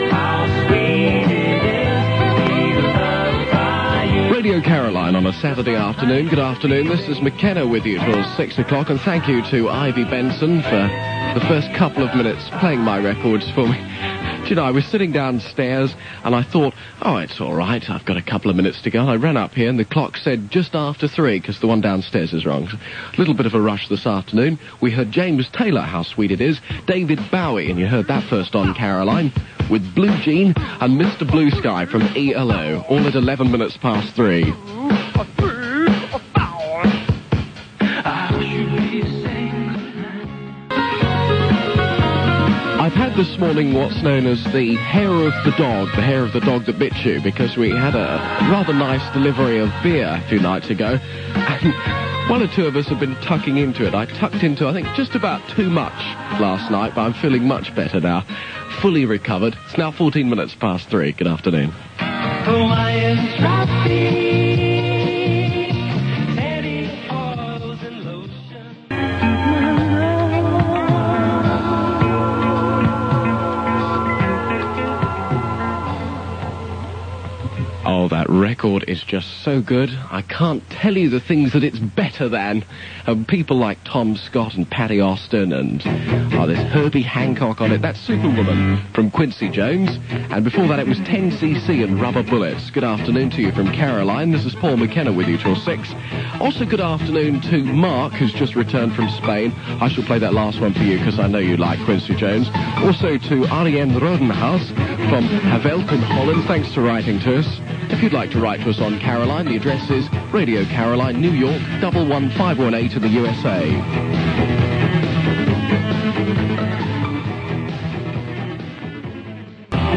click to hear audio Paul McKenna on the afternoon show on Caroline International, 13th October 1984.